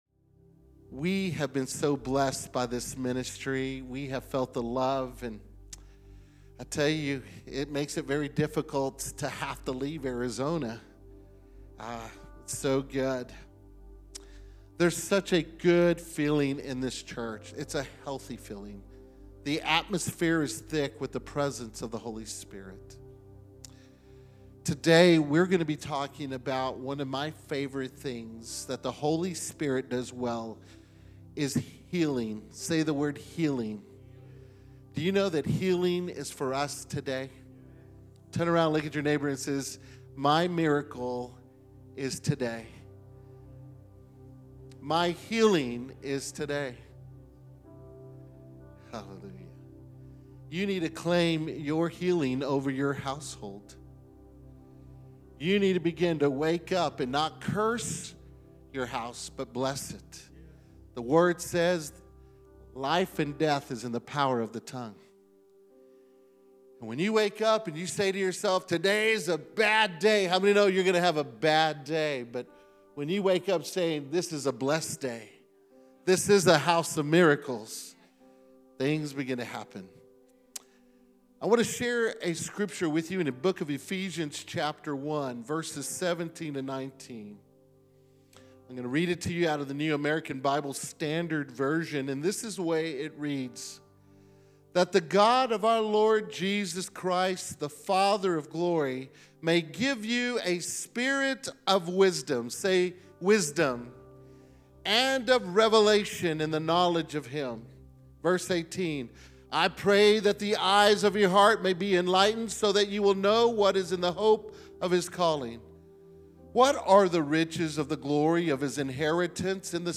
Sermons | Kingdom Church